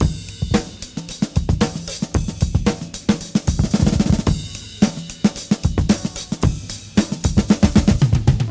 • 113 Bpm Breakbeat E Key.wav
Free breakbeat sample - kick tuned to the E note.
113-bpm-breakbeat-e-key-ANw.wav